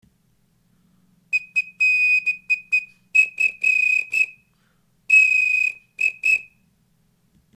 ストローホイッスル写真 ストローホイッスル　＜2006年＞
吹き口のストローを平らにするためと音の鳴る位置を固定するためにクリップを使いました。